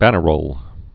(bănə-rōl)